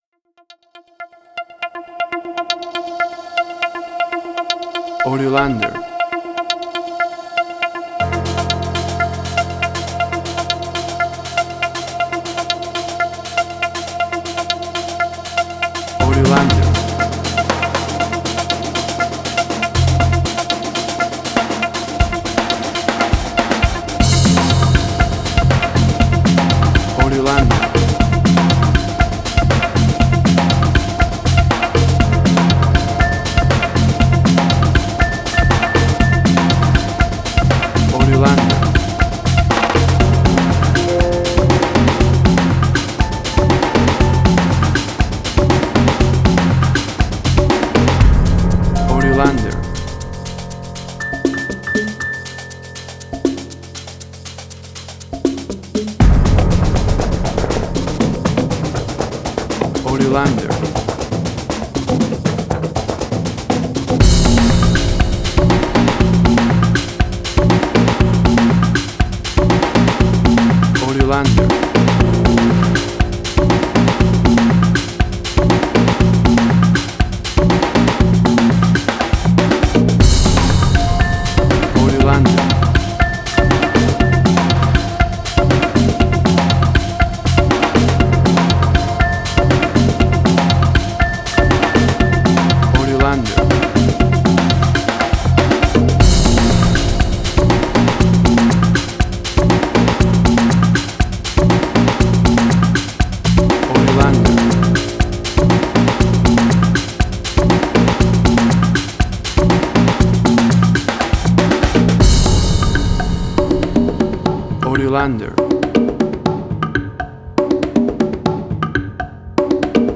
Suspense, Drama, Quirky, Emotional.
WAV Sample Rate: 16-Bit stereo, 44.1 kHz
Tempo (BPM): 120